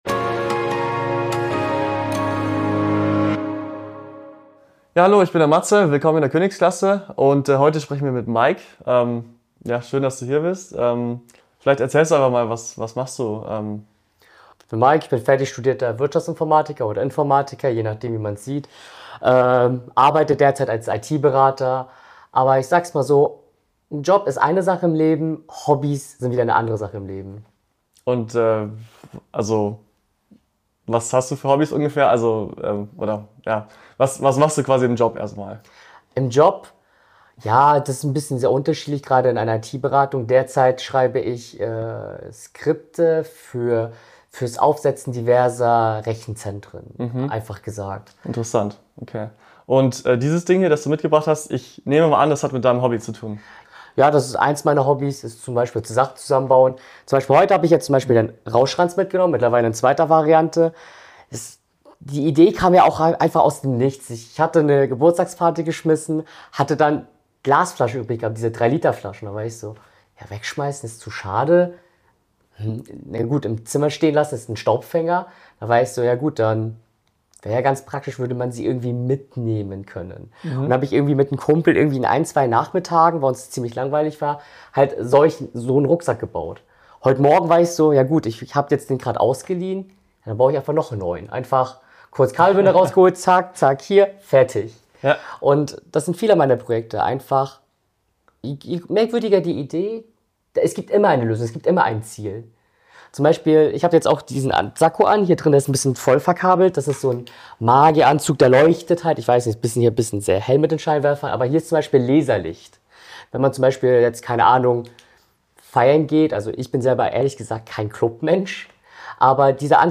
Die Gen Z interviewt CXOs über ihr Leben